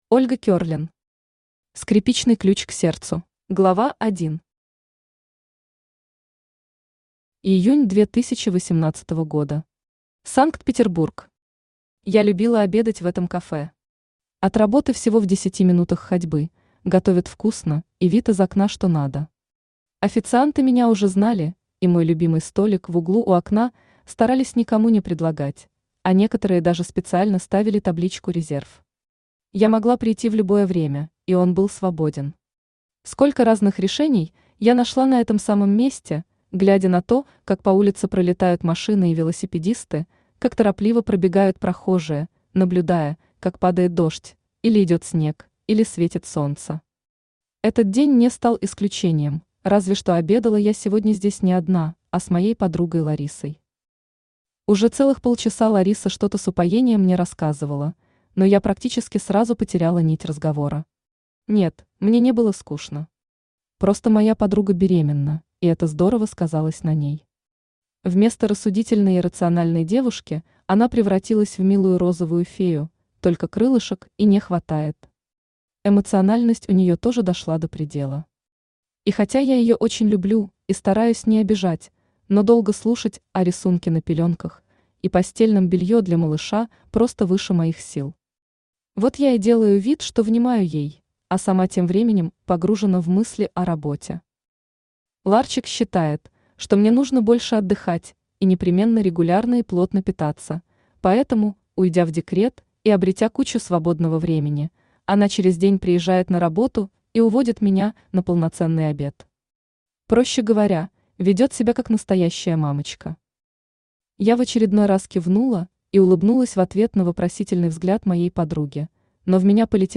Аудиокнига Скрипичный ключ к сердцу | Библиотека аудиокниг
Читает аудиокнигу Авточтец ЛитРес.